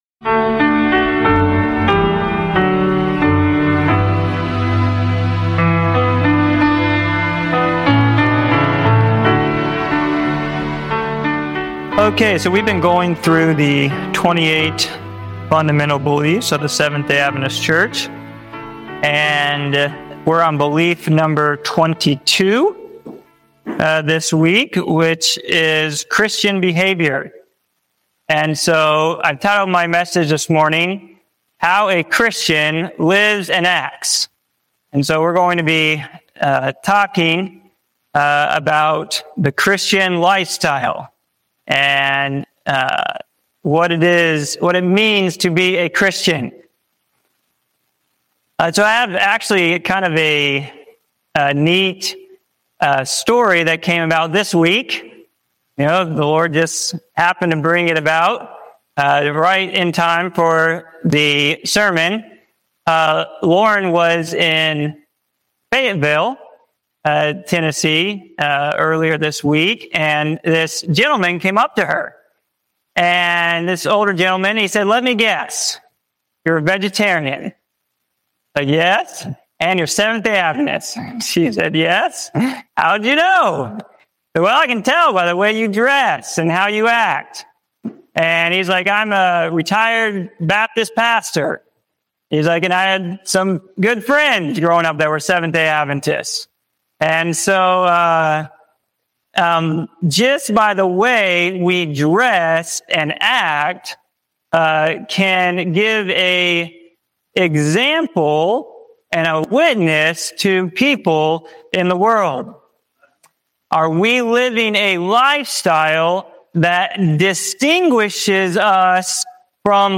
This sermon explores how a Christ-centered lifestyle—through health, modesty, discipline, and daily choices—serves as a living witness to the world. Rooted in grace and empowered by God, it reveals how true obedience flows from love, reflecting holiness in body, mind, and spirit.